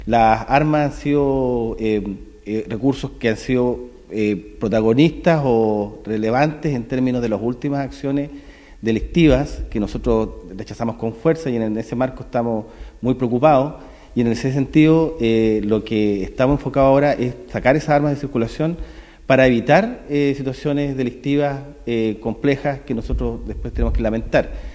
Ante ello, el delegado presidencial regional, Jorge Alvial, destacó el aumento en el retiro de armas y municiones, las que salen de circulación, y permiten evitar situaciones delictivas y complejas, como hechos evidenciados en las últimas semanas.